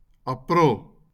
Audio pronunciation file from the Lingua Libre project.